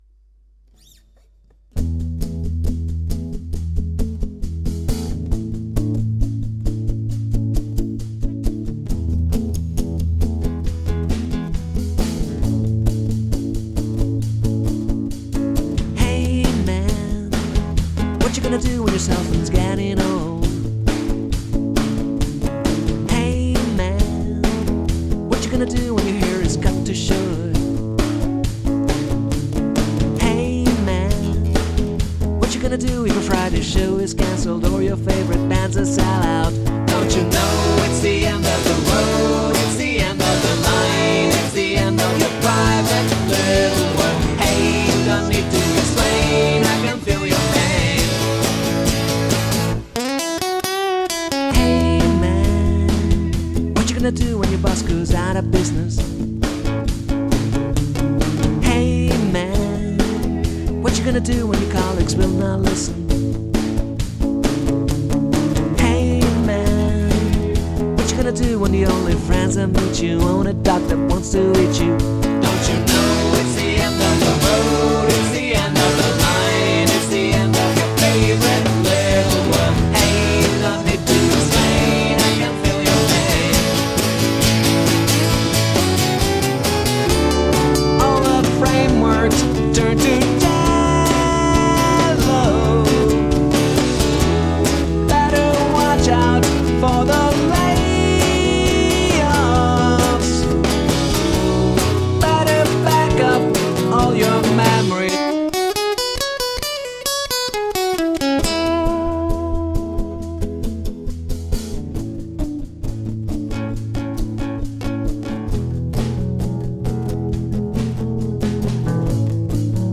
pop-rock band